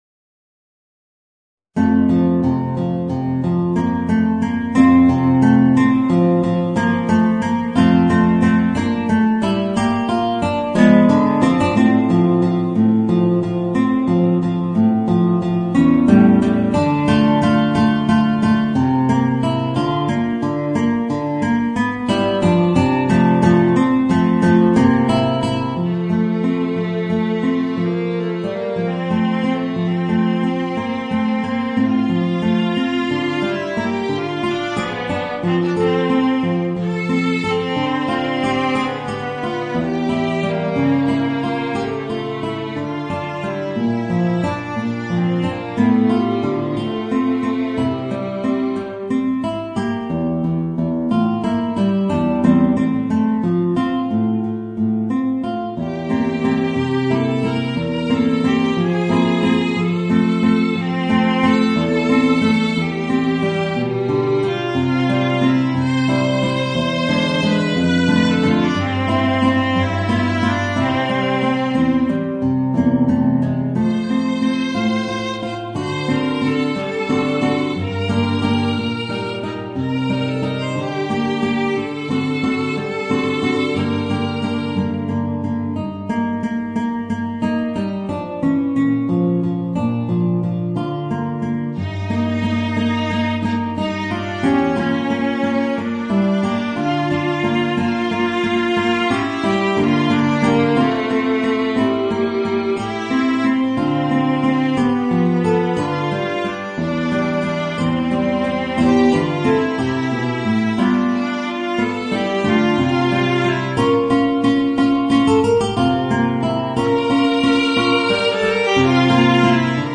Voicing: Guitar and Viola